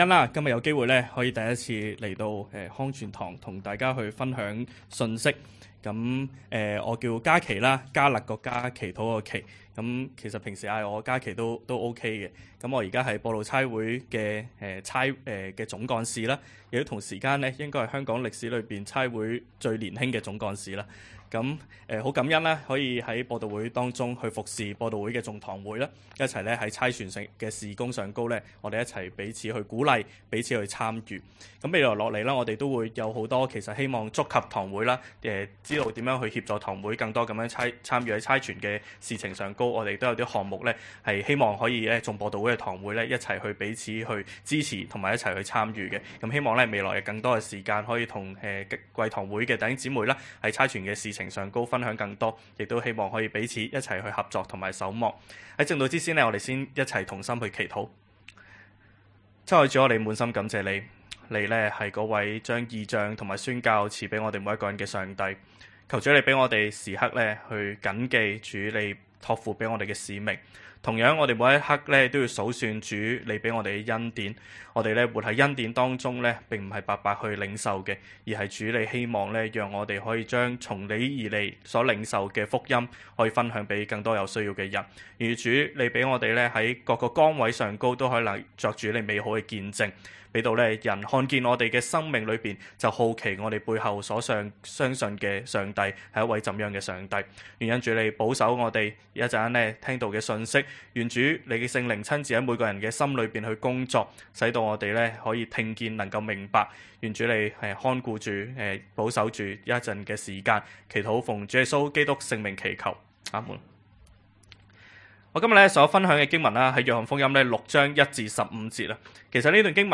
講道 ： 主的供應常足夠